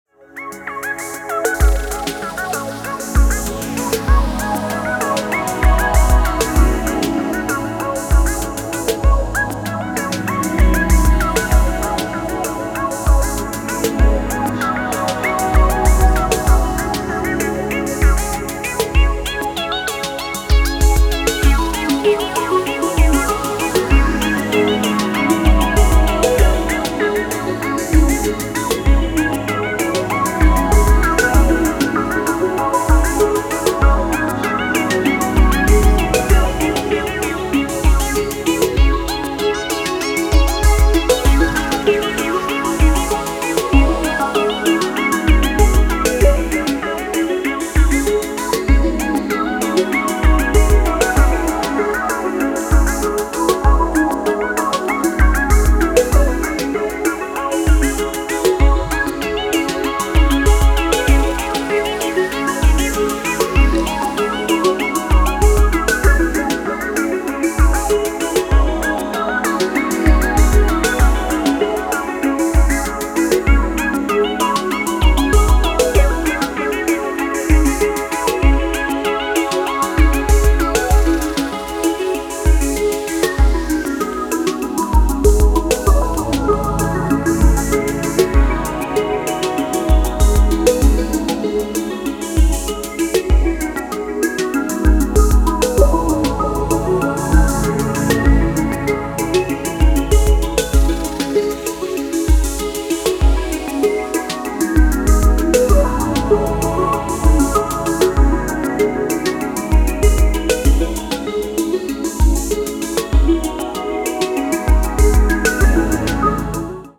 メロウチルアウトなダウンテンポ